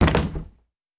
Padding on Glass Normal Hit.wav